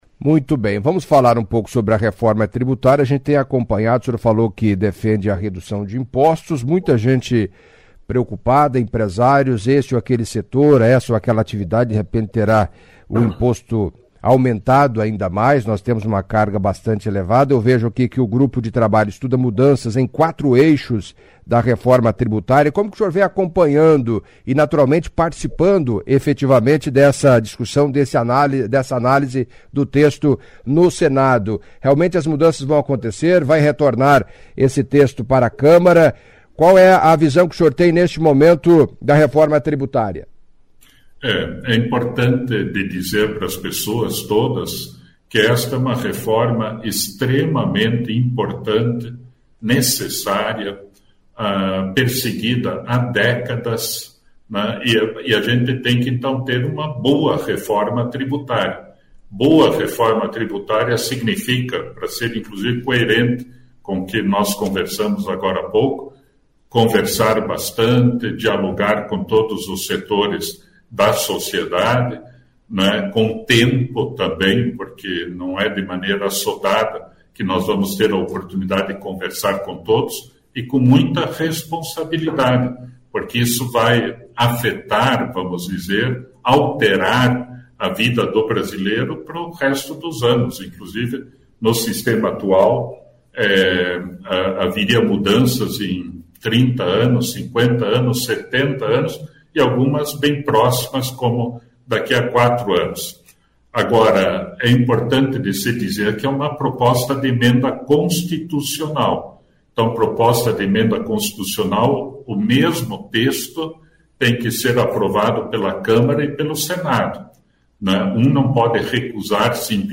Em entrevista à CBN nesta segunda-feira (11) Flávio Arns, senador paranaense do PSB, falou do texto da reforma tributária, que está em análise no Senado.